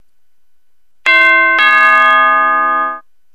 Doorbell - Sound effects . Free ringtones for cell phones